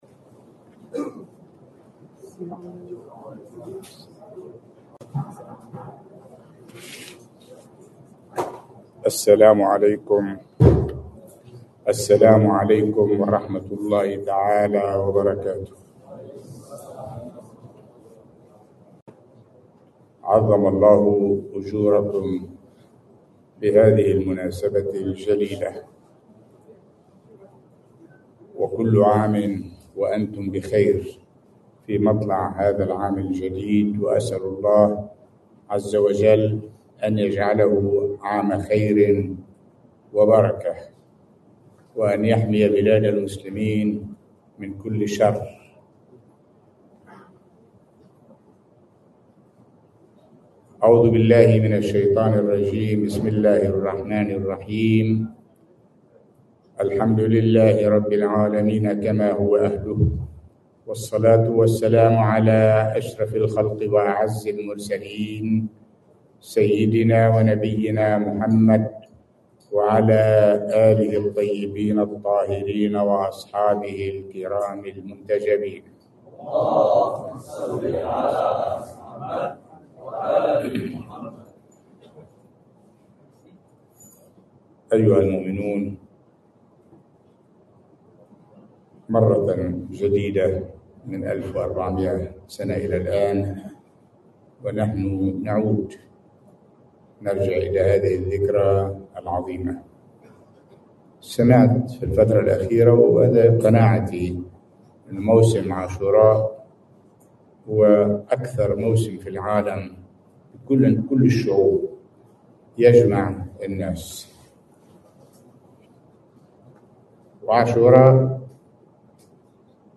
محاضرة
في دكار في قاعة المؤسسة الإسلامية الاجتماعية وعبر البث المباشر في الليلة الأولى من محرم الحرام سنة 1445 هـ.